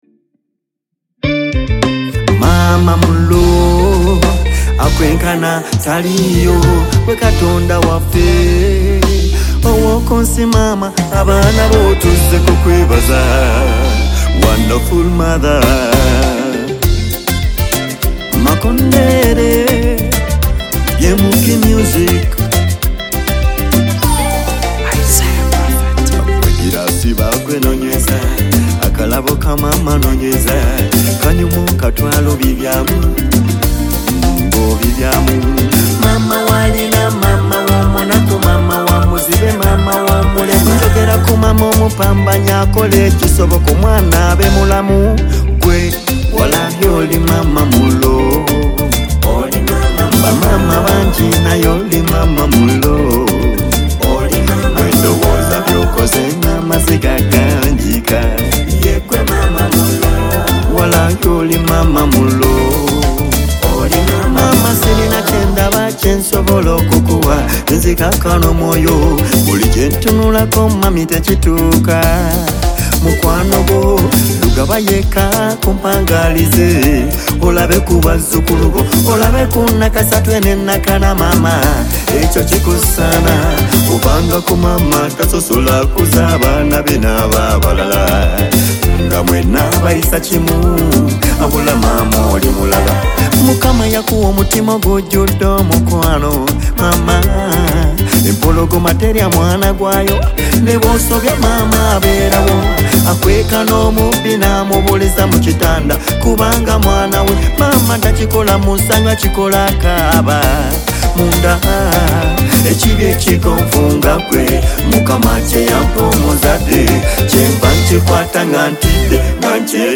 Genre: Ragga